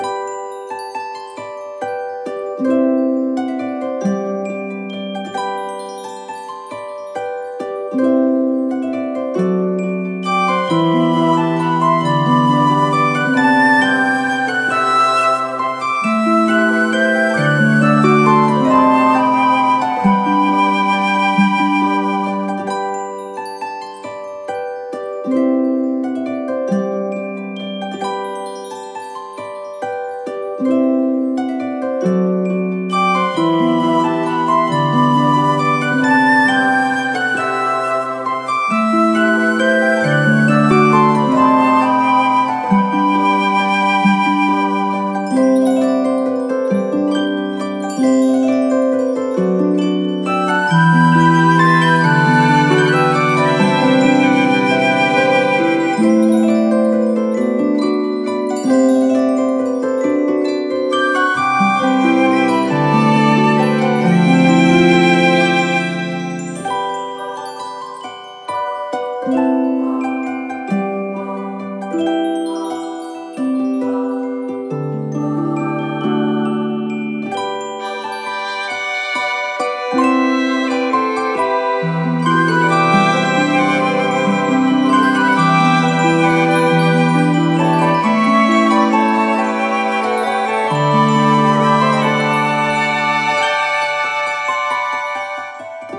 🎻ＢＧＭをご用意しました。